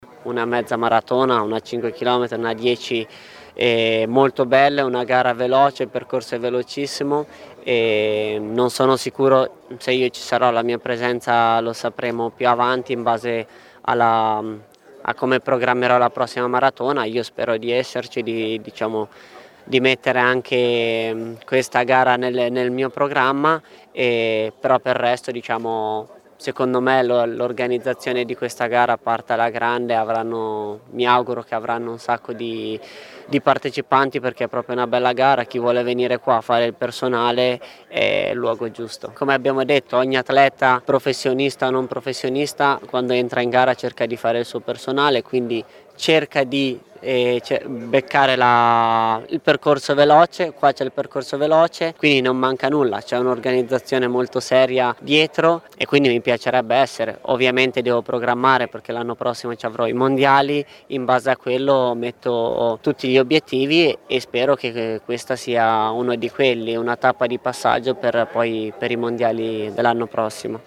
Special guest alla presentazione dell’evento Yemaneberhan Crippa, medaglia d’oro Mezza Maratona Europei Roma 2024: